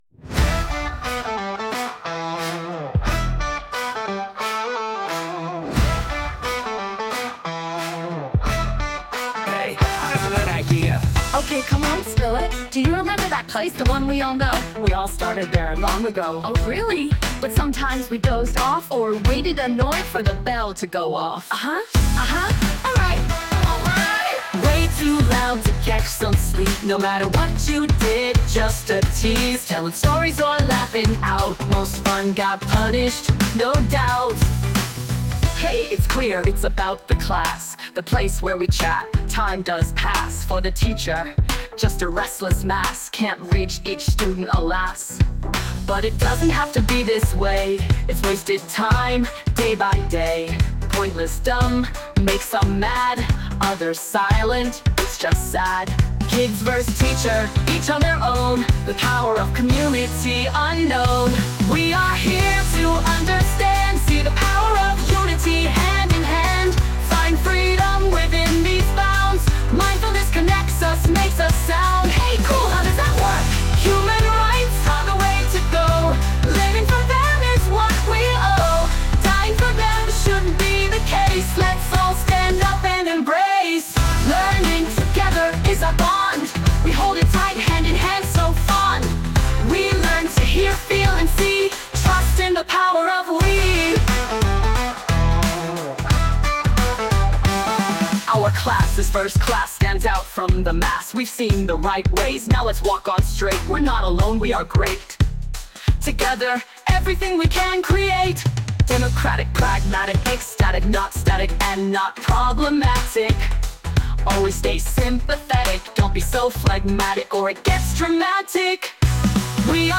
Funky Clave32-Remix